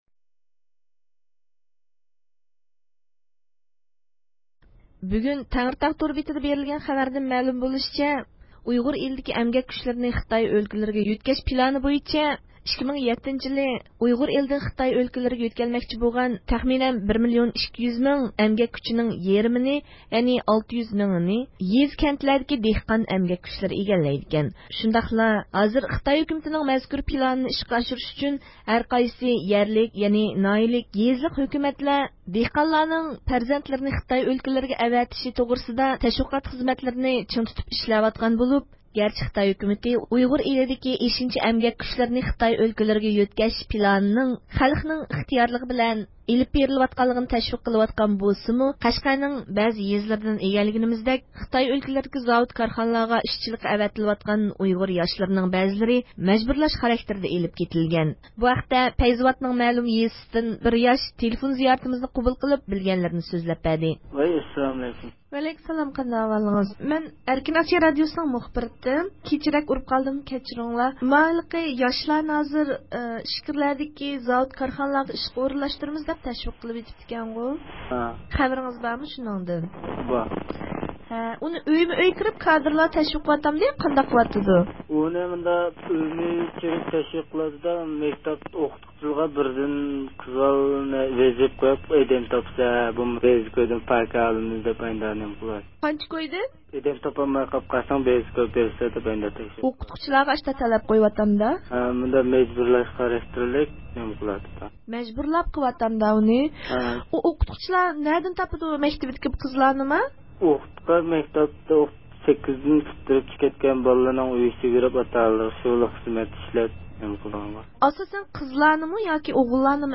بۇ ھەقتە قەشقەر پەيزىۋات ناھىيىسىنىڭ مەلۇم يېزىسىدىن تېلېفون زىيارىتىمىزنى قوبۇل قىلغان بىر ياش ئۆز يېزىسىدا يۈز بېرىۋاتقان ئەھۋاللارنى سۆزلەپ بەردى.